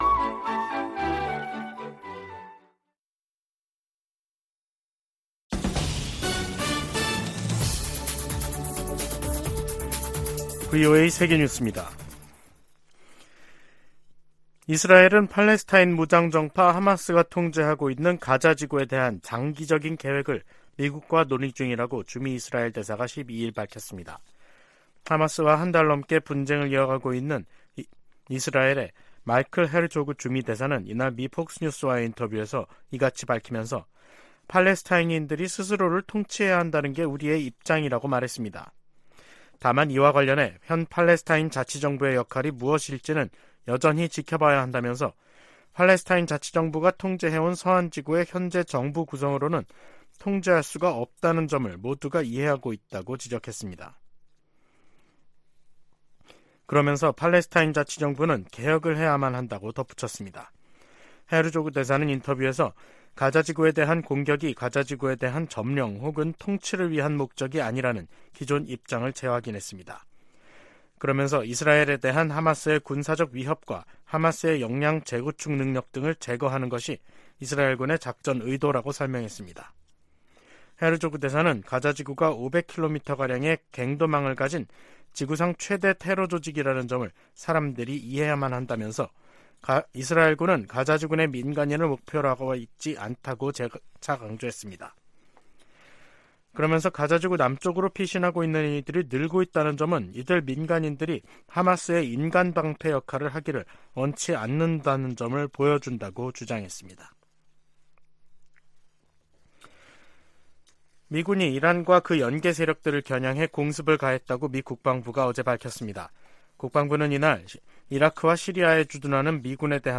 VOA 한국어 간판 뉴스 프로그램 '뉴스 투데이', 2023년 11월 13일 2부 방송입니다. 미국과 한국은 북한의 핵 위협에 대응해 맞춤형 억제전략(TDS)을 10년만에 개정하고, 미군 조기경보위성 정보 공유를 강화하기로 했습니다.